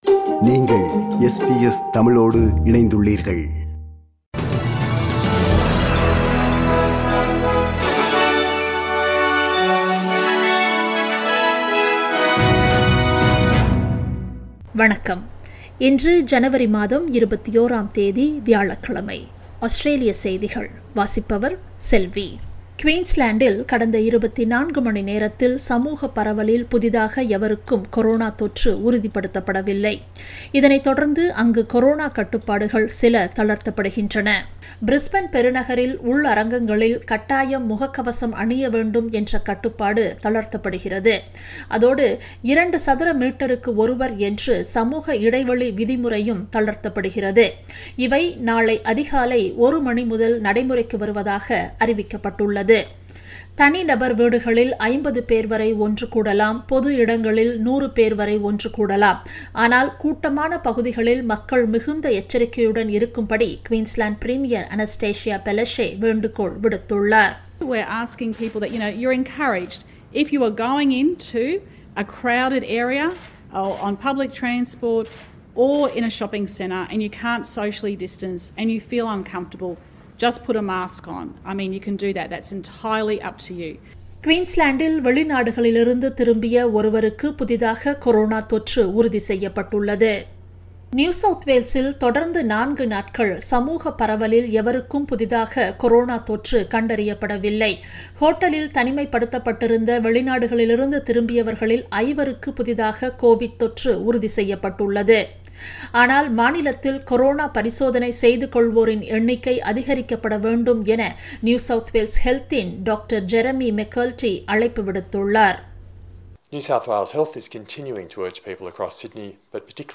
Australian news bulletin for Thursday 21 January 2021.